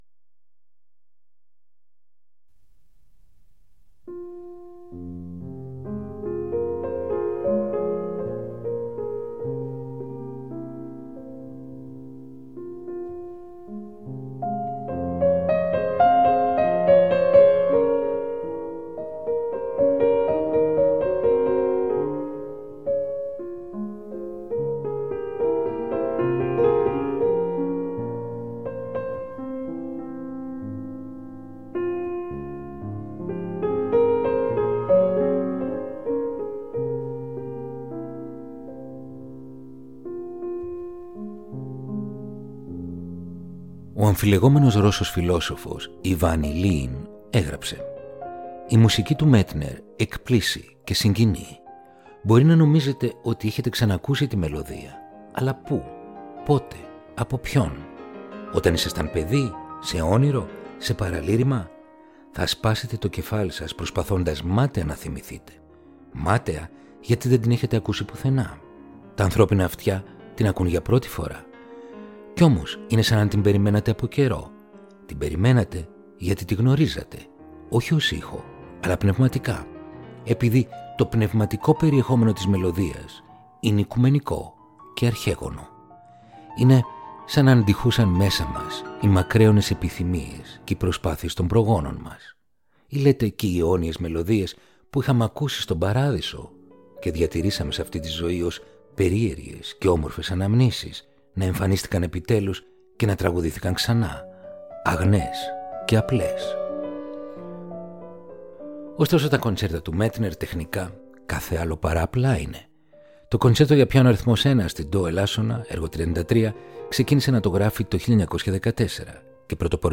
Ρομαντικά κοντσέρτα για πιάνο – Επεισόδιο 26ο